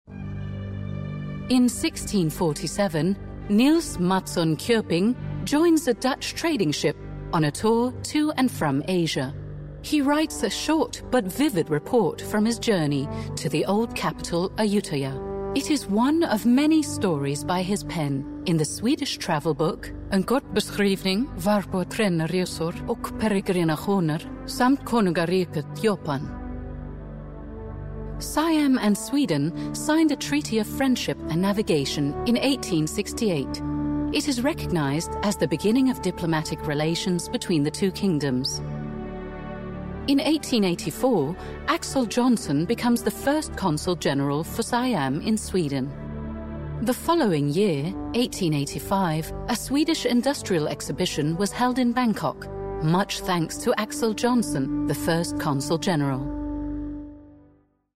English (US) voiceover - EQHO
EQHO provides multi-language solutions from its in-house recording facilities